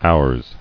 [ours]